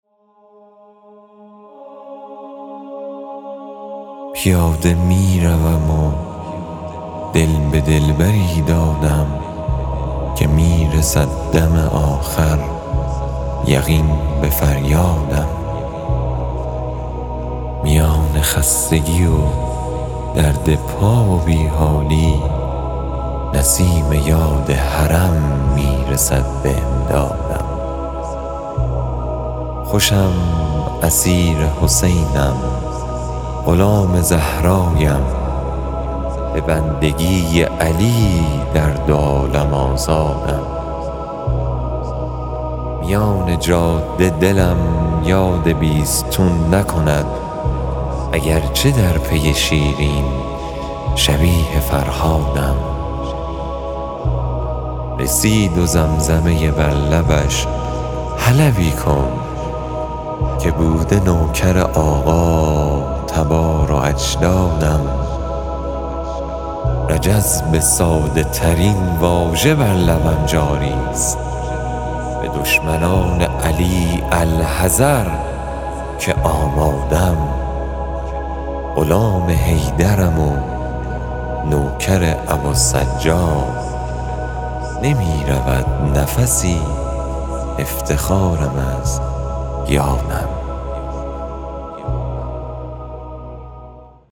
تهیه شده در استودیو نجوا